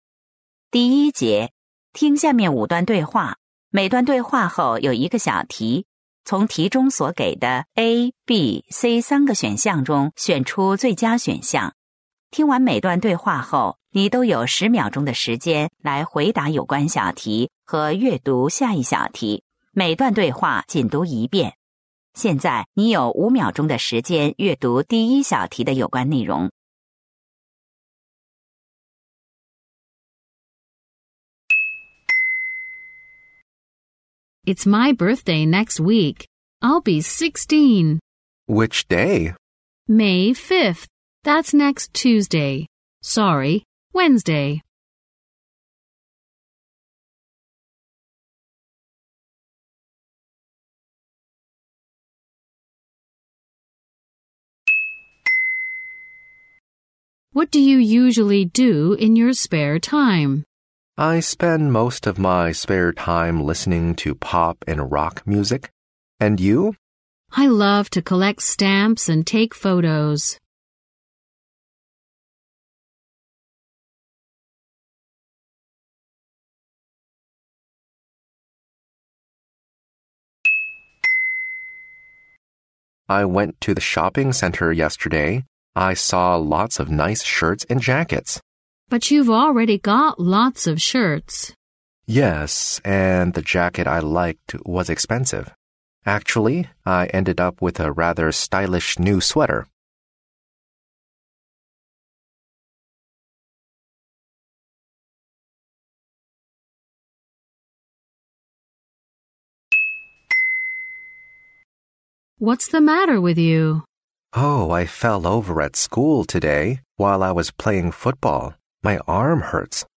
2021黑龙江哈师大附中高一上期中考英语试题及参考答案 英语听力： 点击下载MP3 2021黑龙江哈师大附中高一上期中考各科试题及参考答案汇总 (责任编辑：admin)